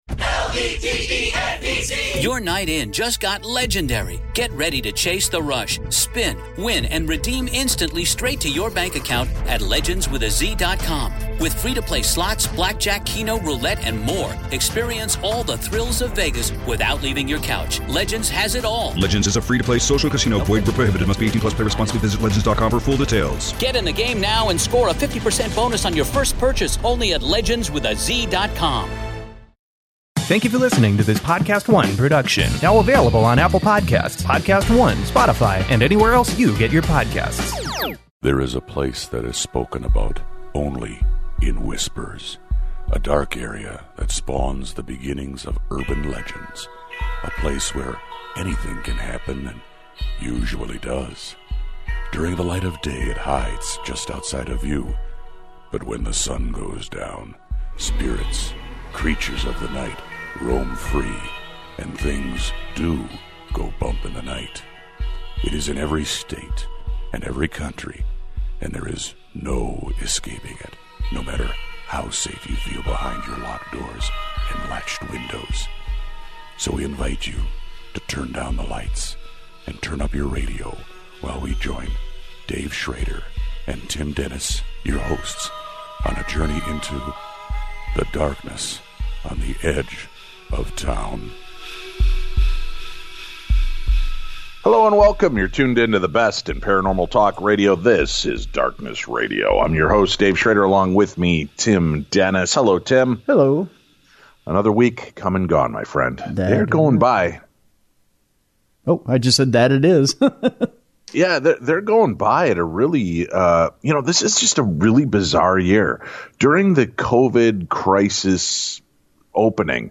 paranormal talk radio